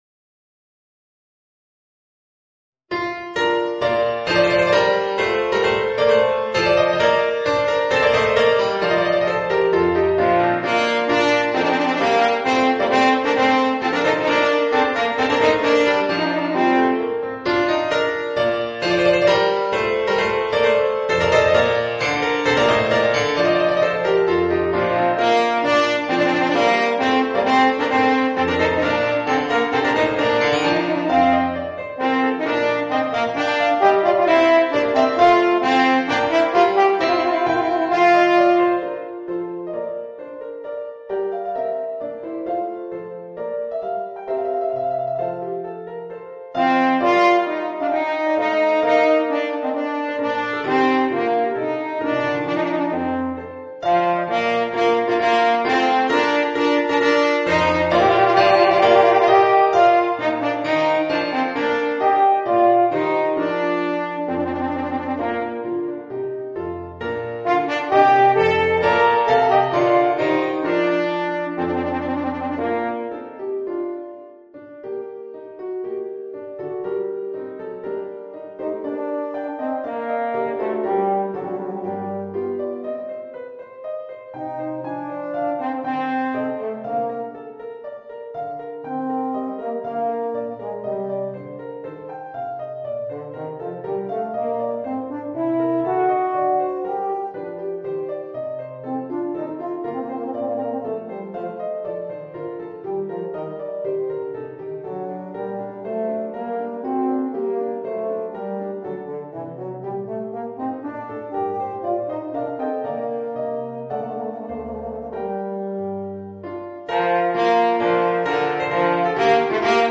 Voicing: Eb Horn and Organ